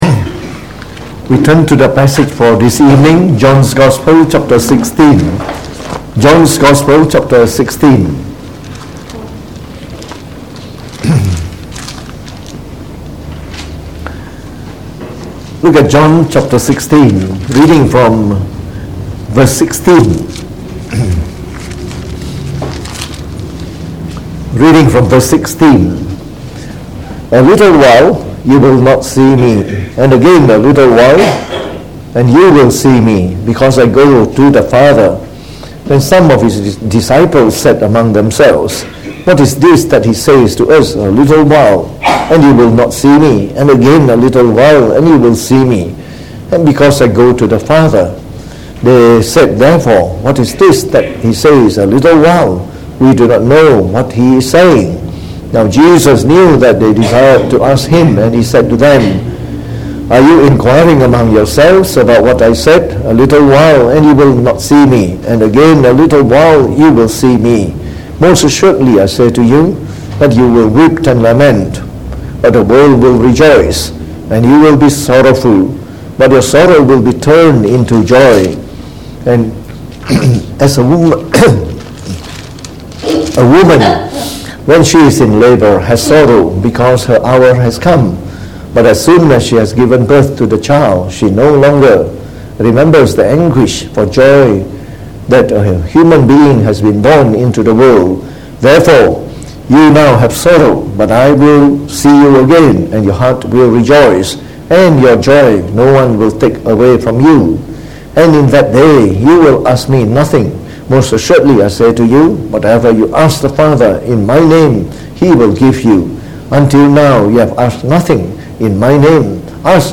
Preached on the 19th May 2019.